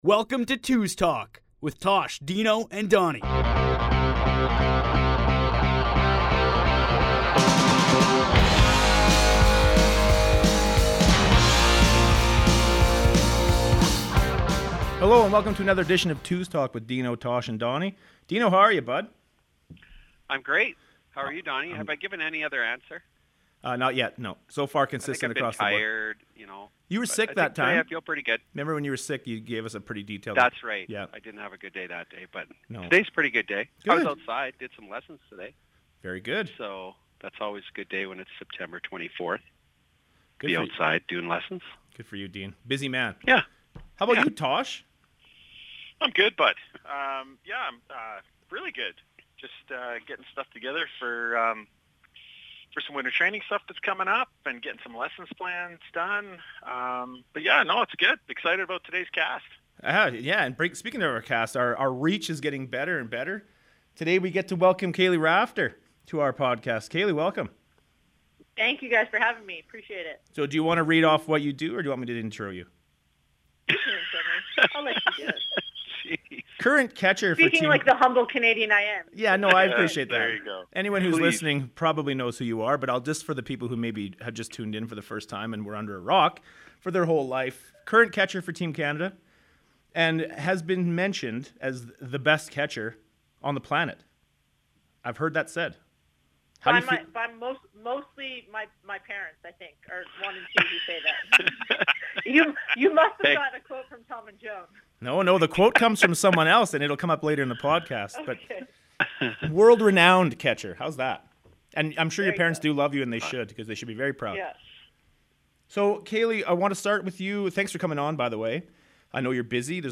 In this episode, the fellas sit down with Team Canada’s veteran catcher, Kaleigh Rafter.